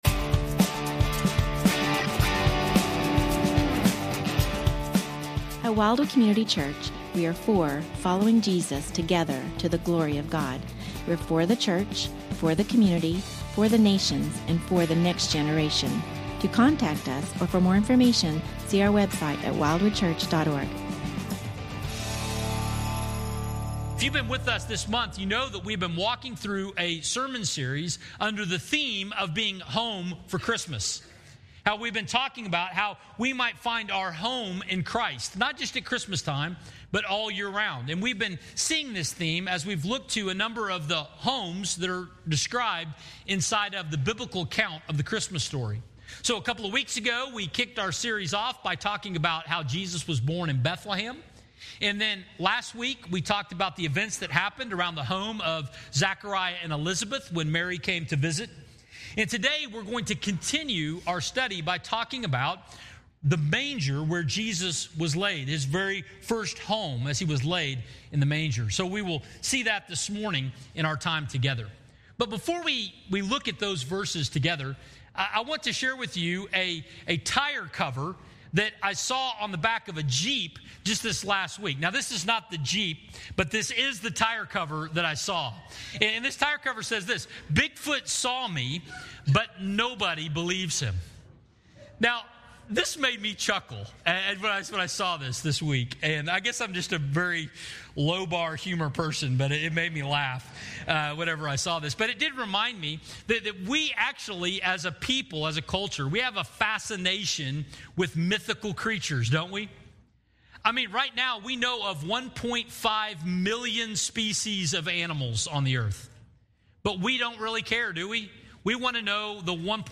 Home for Christmas (part 3) Sermon audio, video, and questions